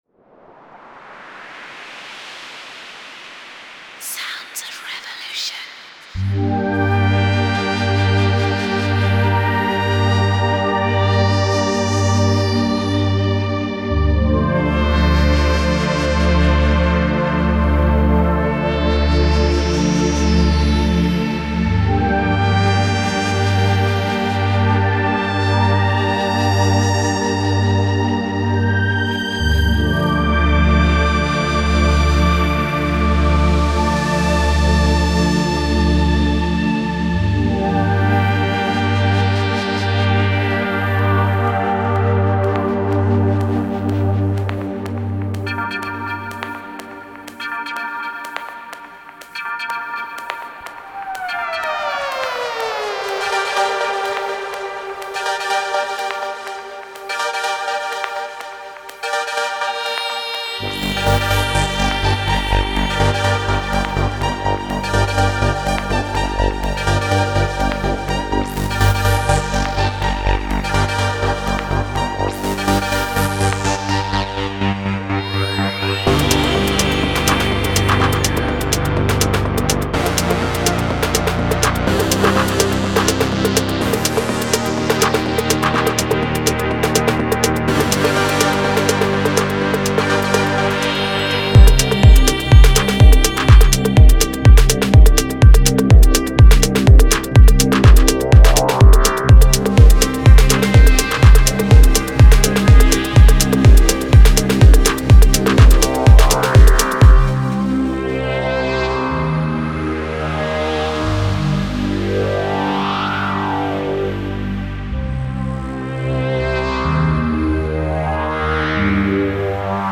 You get evolving ambient textures, organic pads, classic house stabs, hard-pumping basses, unique effects, glassy, cold, powerful leads, and many wonderful analog-sounding patches.
The download of ‘SOR Waldorf Microwave Presets’ includes the original synth patches (MIDI File dump) I produced for the classic hardware synthesizer (128 patches), as well as a revised and perfected new version for the Plugin (131 patches). 24 MIDI files are also included for musical inspiration and context; you can hear them in the demo.
Please note: Since the Microwave has no internal effects, a few standard effect plugins were used for the demo. All sounds, except the drums, are from the Microwave 1 plugin.
DEMO MP3